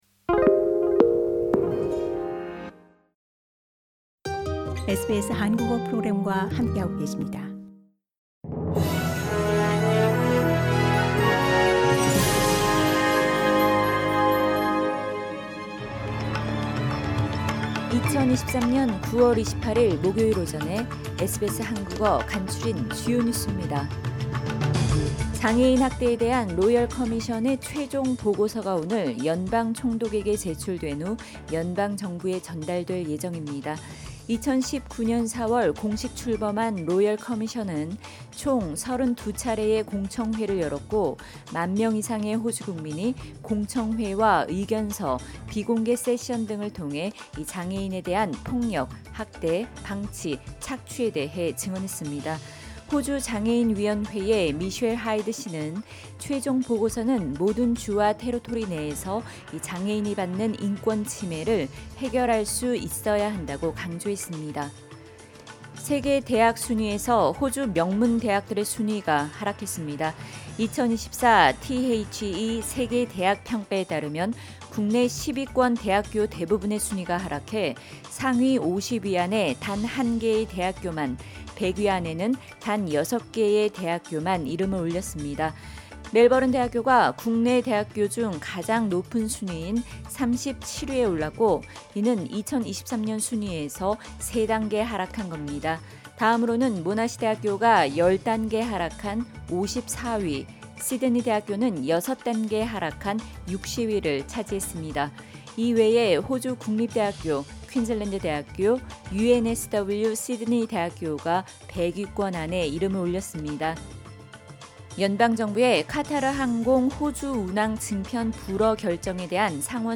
2023년 9월28일 목요일 아침 SBS 한국어 간추린 주요 뉴스입니다.